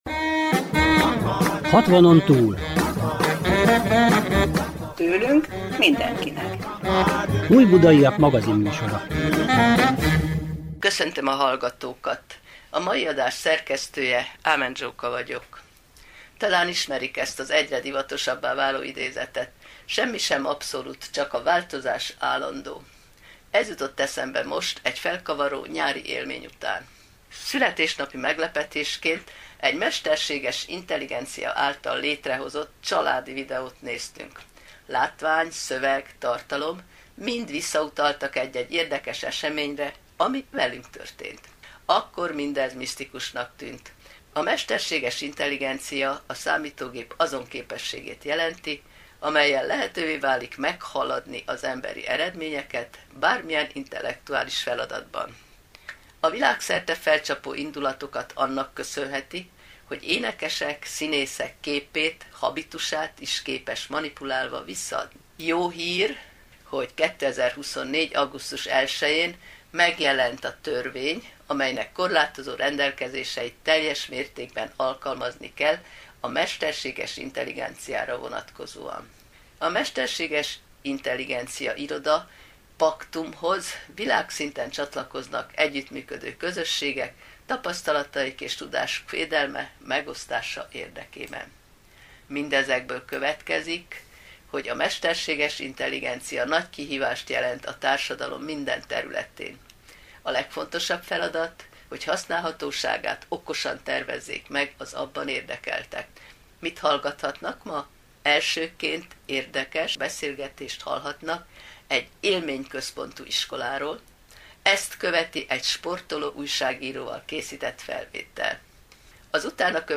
Magazinműsor a Civil Rádióban